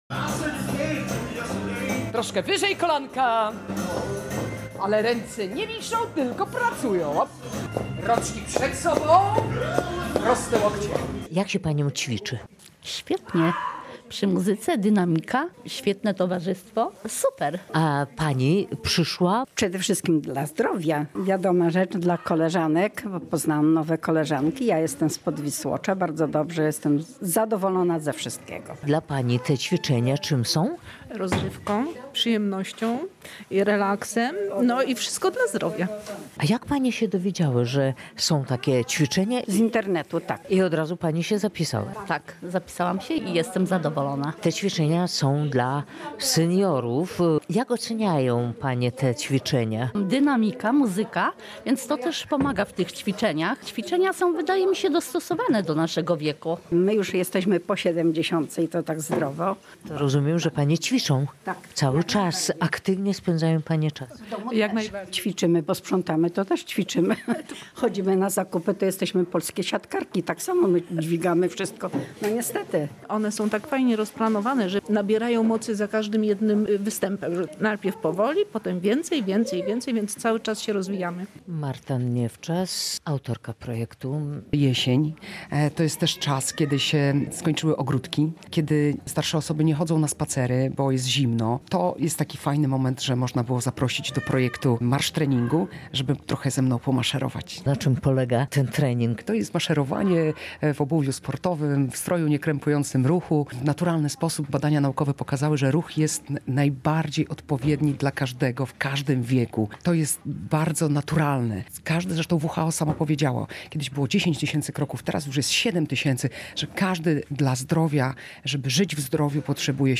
Jest to zarówno sport jak i rozrywka mówią uczestniczki zajęć.